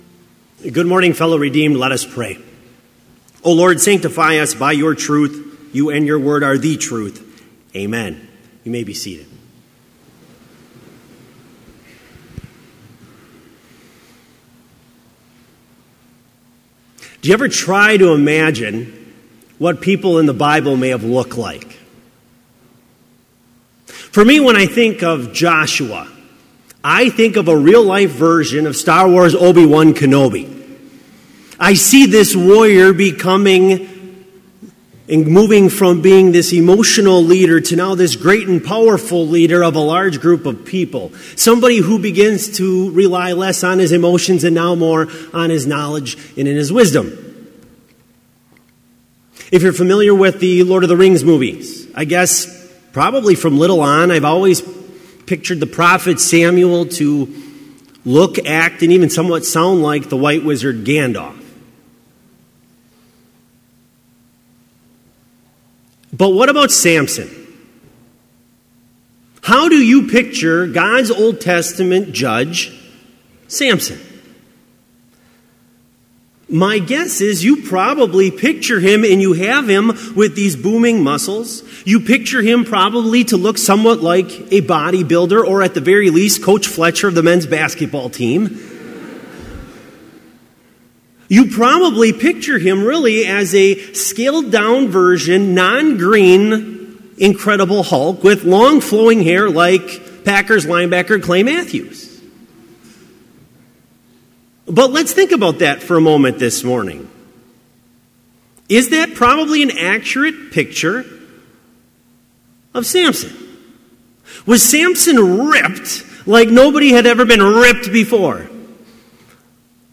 Complete service audio for Chapel - October 17, 2017